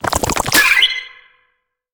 Sfx_creature_penguin_skweak_14.ogg